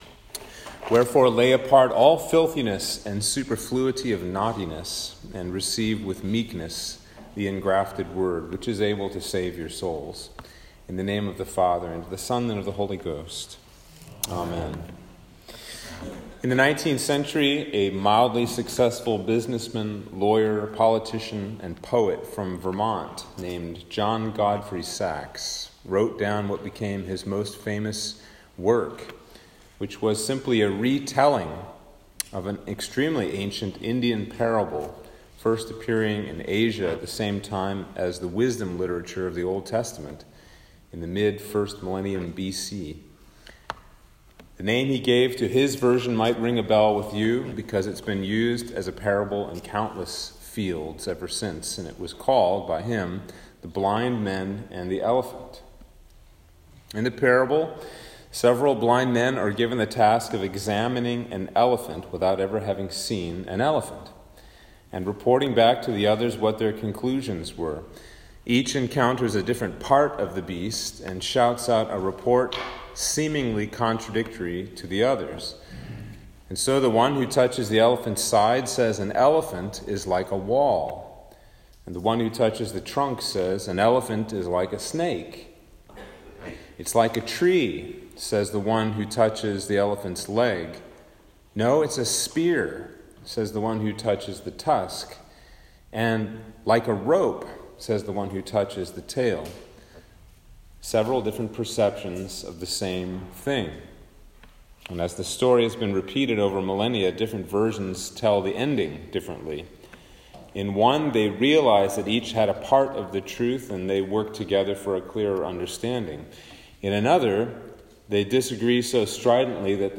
Sermon for Easter 4
Sermon-for-Easter-4-2021.m4a